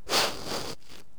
firearrowhit3.wav